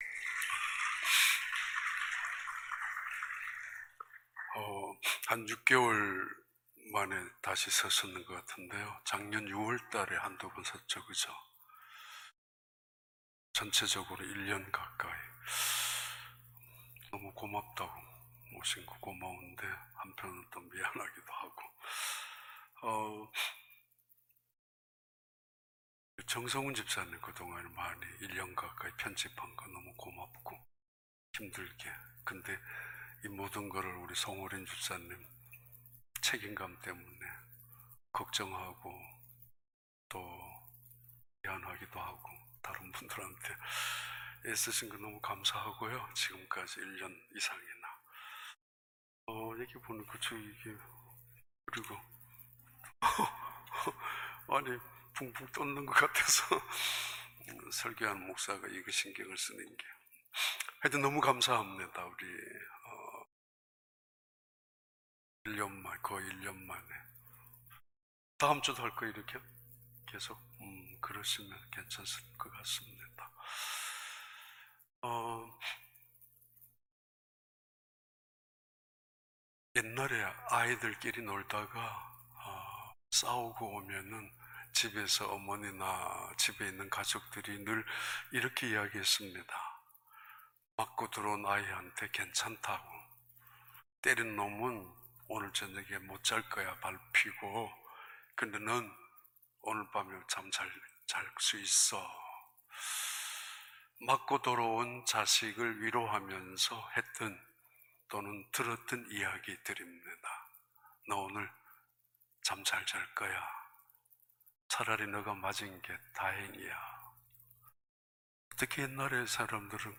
2021년 2월 7일 주일 4부 예배